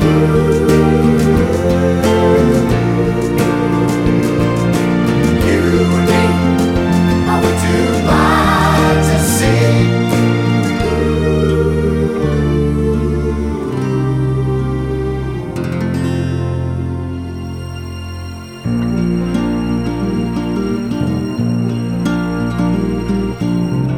no Backing Vocals Pop (1960s) 3:15 Buy £1.50